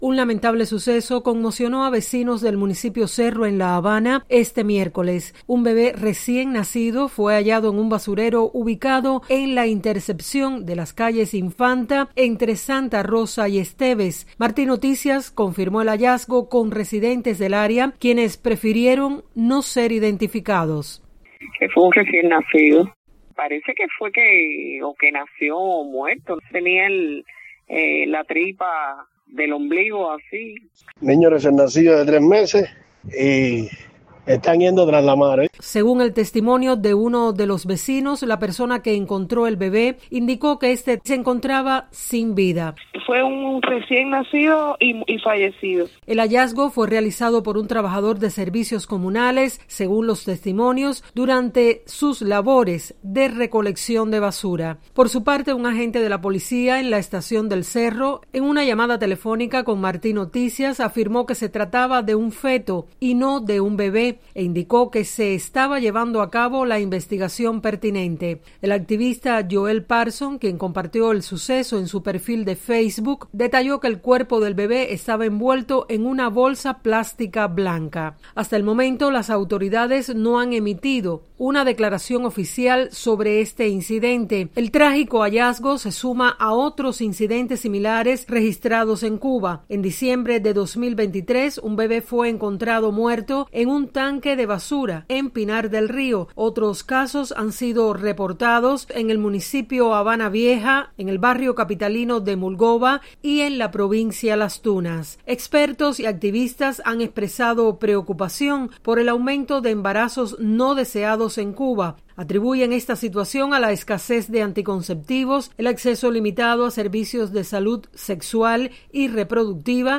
Testimonios: Bebé recién nacido fue hallado en un contenedor de basura en La Habana
Vecinos del área en el que fue encontrado un bebé recién nacido en La Habana explicaron lo ocurrido a Martí Noticias.